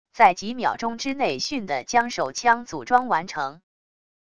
在几秒钟之内迅地将手枪组装完成wav音频